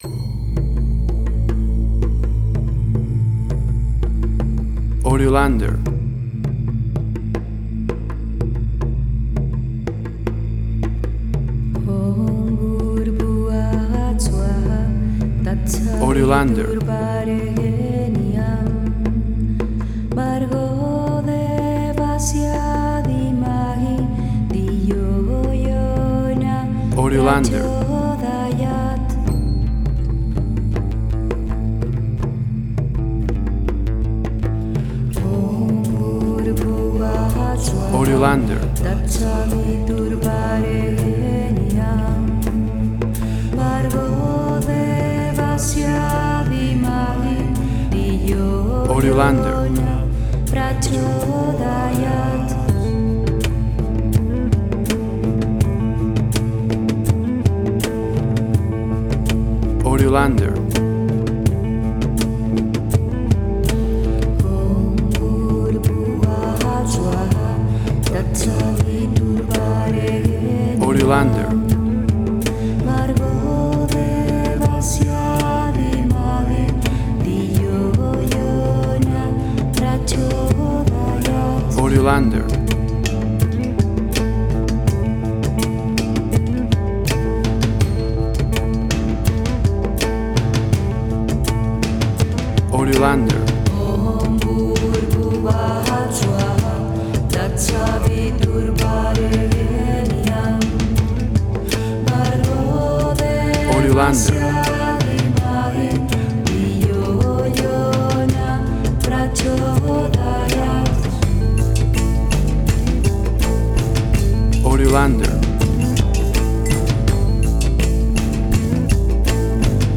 Peacefull and inspiring old mantra
Tempo (BPM): 82